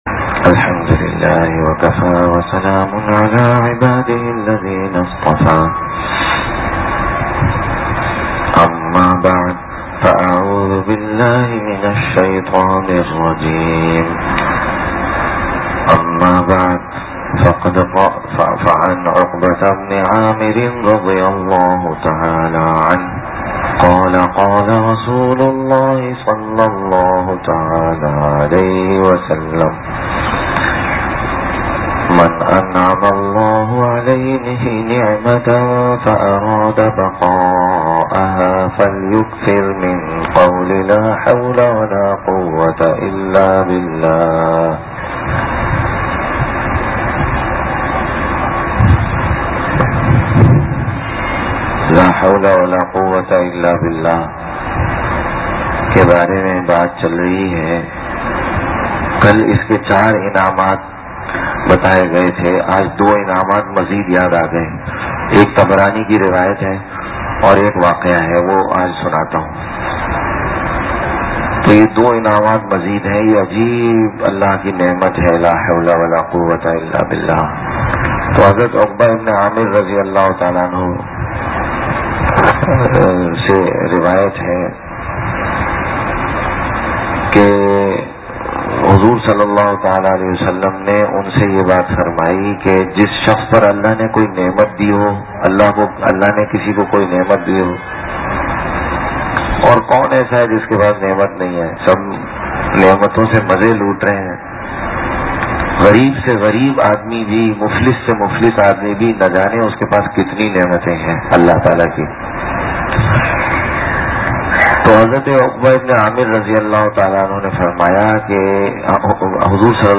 Dars e Hadees-Asar majlis-010716